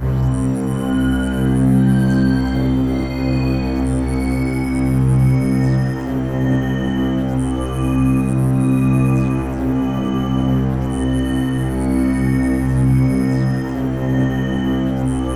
Index of /90_sSampleCDs/USB Soundscan vol.13 - Ethereal Atmosphere [AKAI] 1CD/Partition D/01-SLOWMOTI